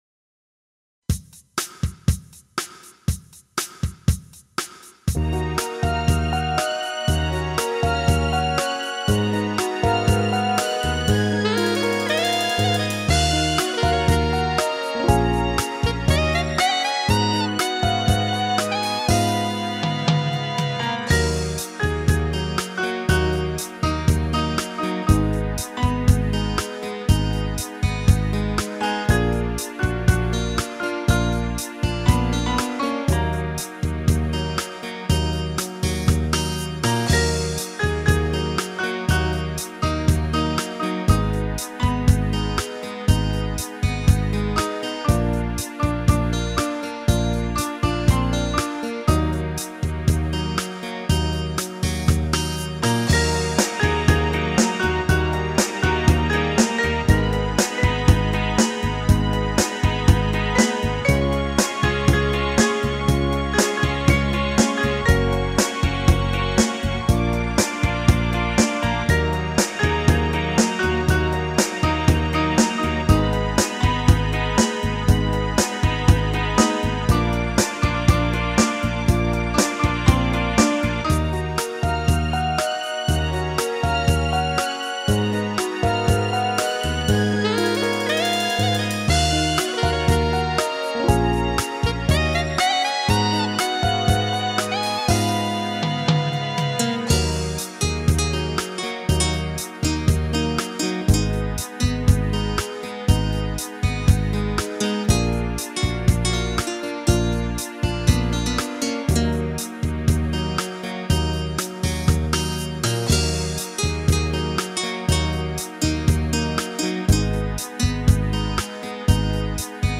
Это просто из опыта освоения синтезатора...